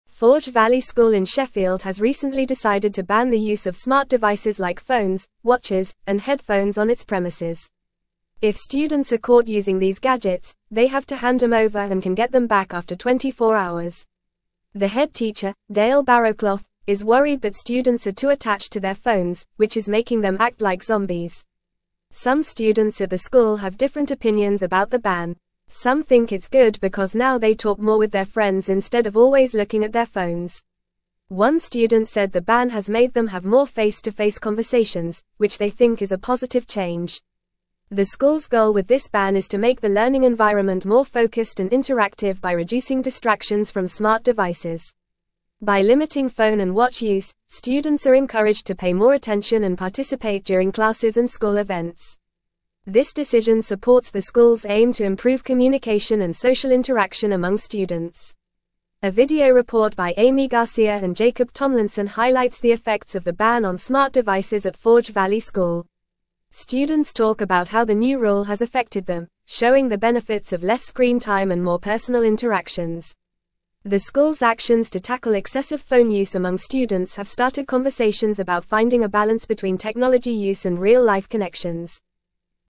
Fast
English-Newsroom-Upper-Intermediate-FAST-Reading-Forge-Valley-School-Implements-Ban-on-Smart-Devices.mp3